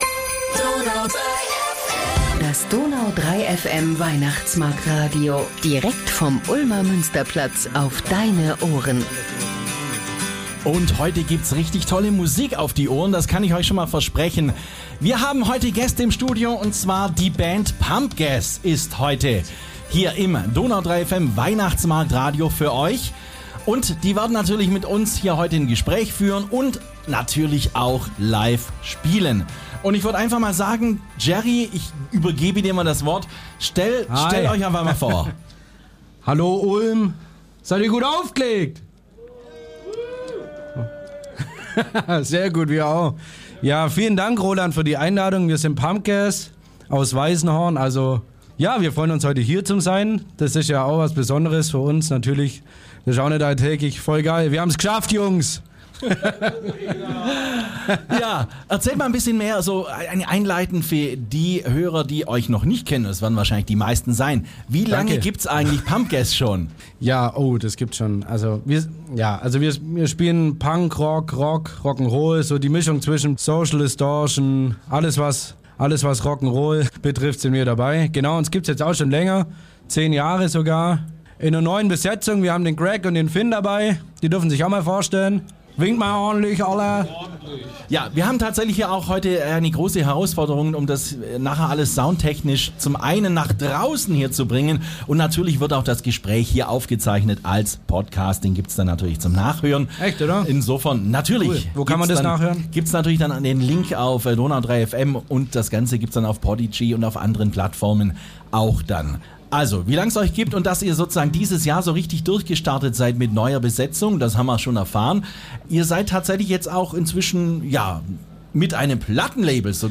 Außerdem haben die 4 Jungs aus Weißenhorn auch einige ihrer Songs LIVE & Acoustic im DONAU 3 FM Weihnachtsmarkt-Studio performt.